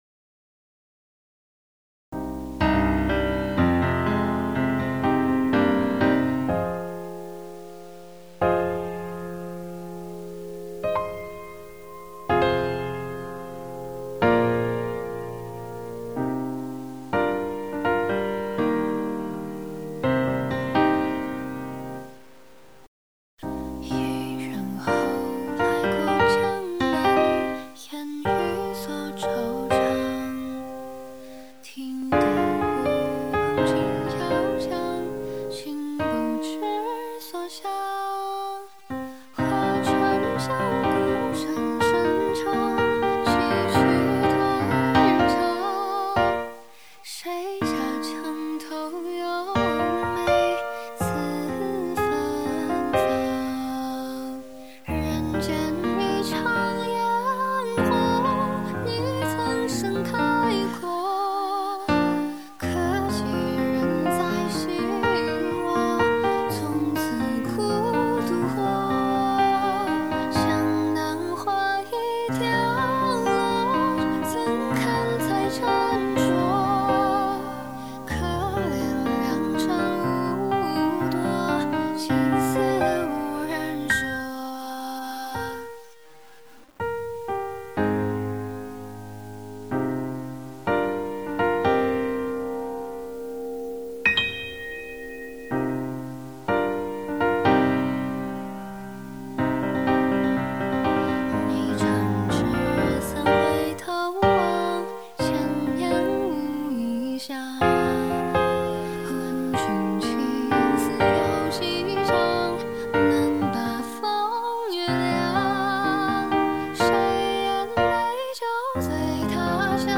Chinese pop song